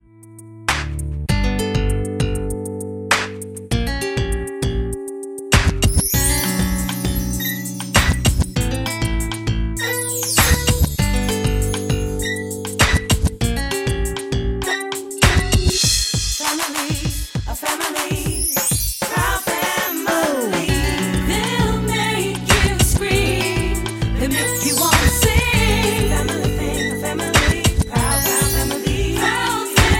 Backing track files: Musical/Film/TV (484)